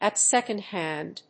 アクセントat sécond hánd